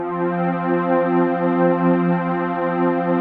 XXL 800 Pads